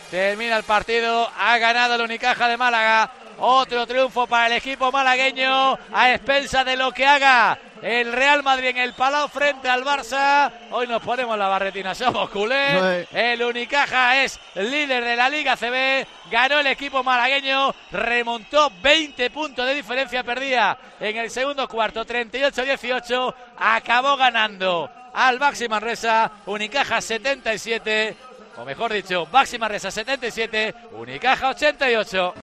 Así te hemos narrado la victoria de Unicaja en Manresa (77-88)